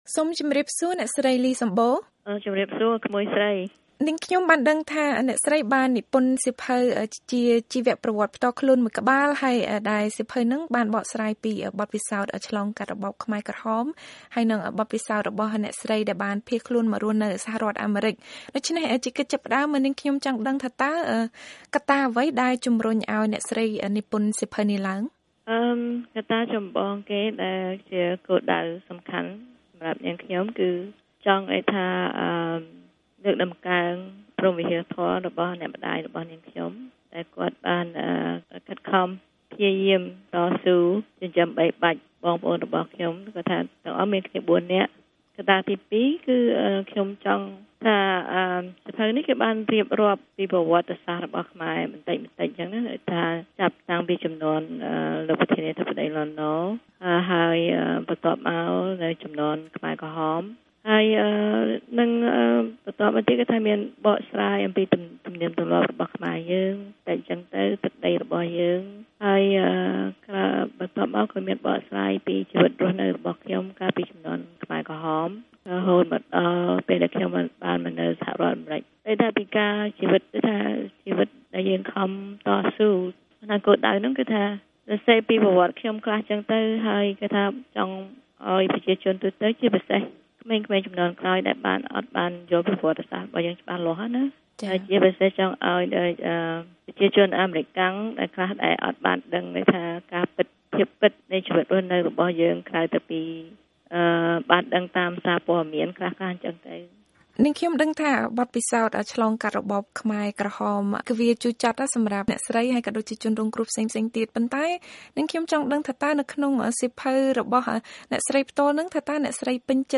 បទសម្ភាសន៍ VOA៖ ការនិពន្ធសៀវភៅជួយដល់ការផ្សះផ្សារផ្លូវចិត្ត